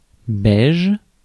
Ääntäminen
Synonyymit marron clair crème isabelle grège Ääntäminen France (Paris): IPA: /bɛʒ/ Haettu sana löytyi näillä lähdekielillä: ranska Käännös Ääninäyte Substantiivit 1. beige US Adjektiivit 2. beige US Suku: f .